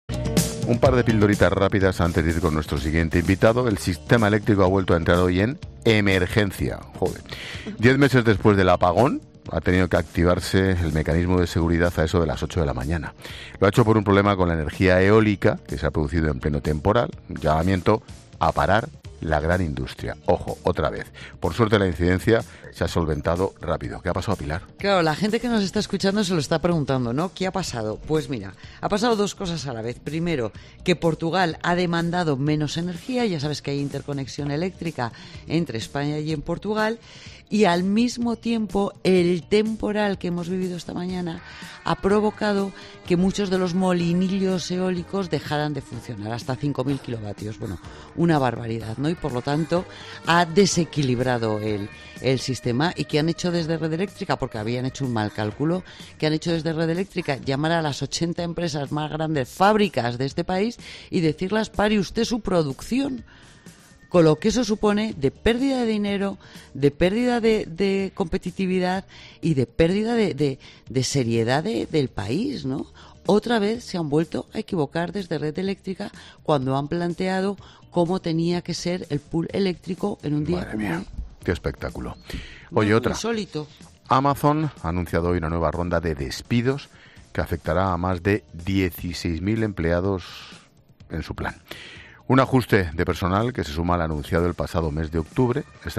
Expósito aprende en Clases de Economía con la directora de Mediodía COPE y experta económica, Pilar García de la Granja, sobre la emergencia en el sistema eléctrico español